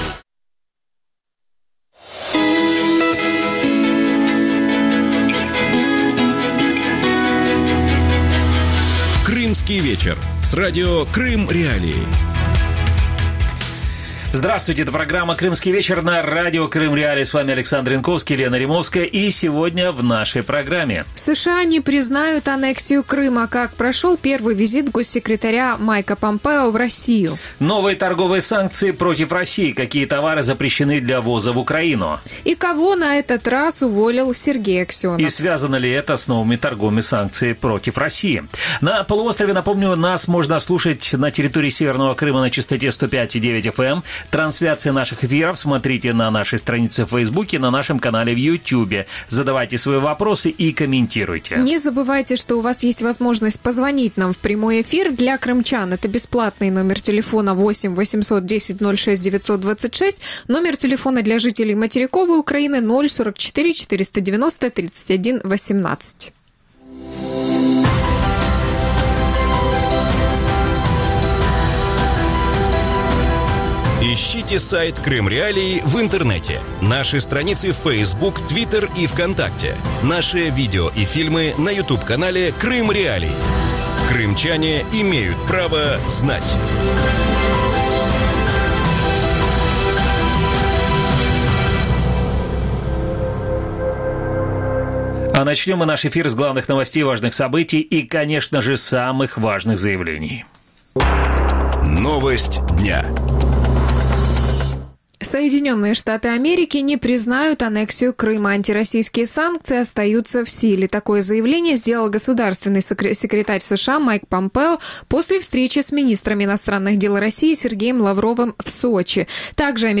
Гости эфира